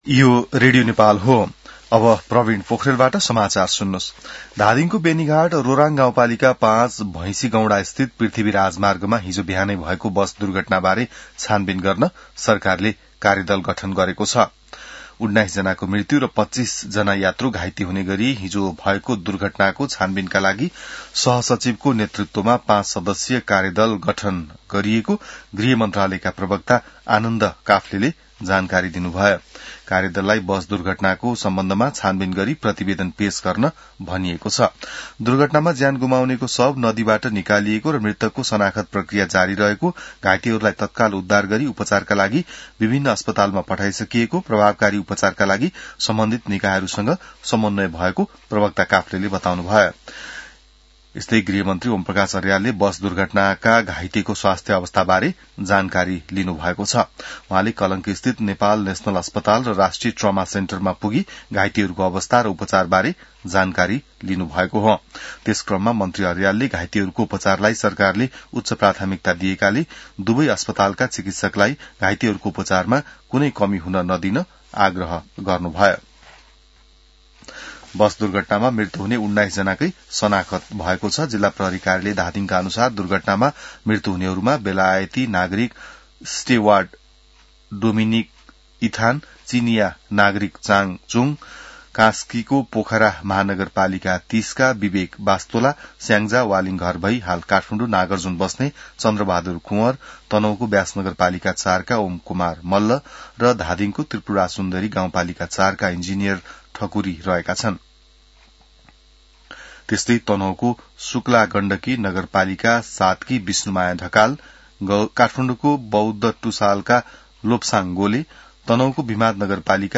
बिहान ६ बजेको नेपाली समाचार : १२ फागुन , २०८२